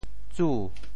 「沮」字用潮州話怎麼說？
沮 部首拼音 部首 氵 总笔划 8 部外笔划 5 普通话 jǔ jù 潮州发音 潮州 zu2 文 中文解释 沮 <動> (形聲。